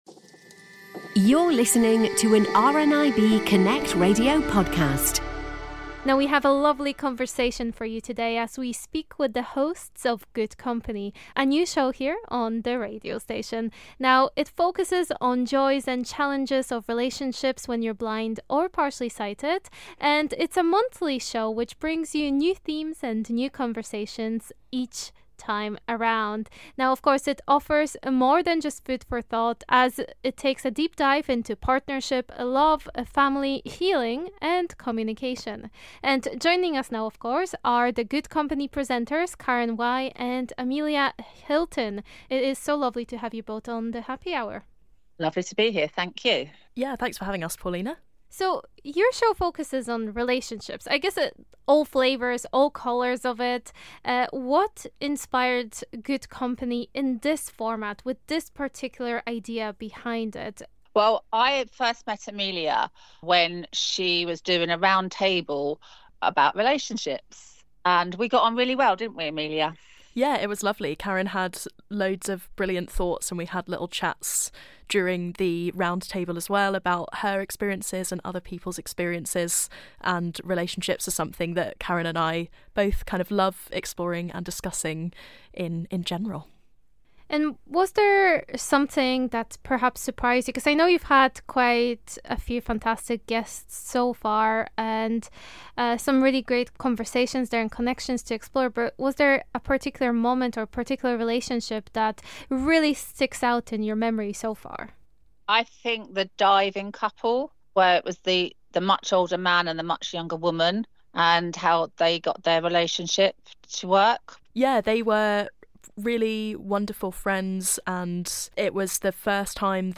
We have a lovely conversation as speak with the hosts of Good Company, a new show here on our radio station.